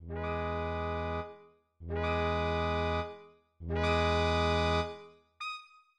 << \new Staff << \relative c''' { \time 4/4 \tempo 4=400 \key e \major \set Staff.midiInstrument="trumpet" \p \< r4. dis8~ dis2~ | dis1 | r1 | r4. dis8~ dis2~ | dis1 | r1 | r4. dis8~ dis2~ | dis1 \ff | r1 | dis4 r2. | } >> \new Staff << \relative c''' { \time 4/4 \tempo 4=400 \key e \major \set Staff.midiInstrument="trumpet" \p \< r4. d8~ d2~ | d1 | r1 | r4. d8~ d2~ | d1 | r1 | r4. d8~ d2~ | d1 \ff | r1 | r1 | } >> \new Staff << \relative c'' { \key e \major \set Staff.midiInstrument="trumpet" \p \< r4 b4~ b2~ | b1 | r1 | r4 b4~ b2~ | b1 | r1 | r4 b4~ b2~ | b1 \ff | r1 | r1 | } >> \new Staff << \relative c' { \key e \major \clef bass \set Staff.midiInstrument="trombone" \p \< r8 gis4.~ gis2~ | gis1 | r1 | r8 gis4.~ gis2~ | gis1 | r1 | r8 gis4.~ gis2~ | gis1 \ff | r1 | r1 | } >> \new Staff << \relative c, { \key e \major \clef bass \set Staff.midiInstrument="tuba" \p \< e1~ | e1 | r1 | e1~ | e1 | r1 | e1~ | e1 \ff | r1 | r1 | } >> >>